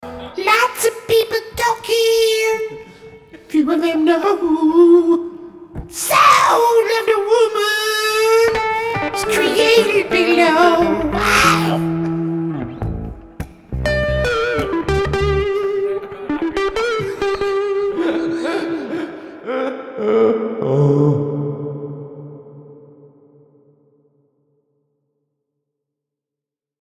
Rehearsals 3.3.2012